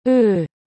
Aprenda a pronunciar o Ö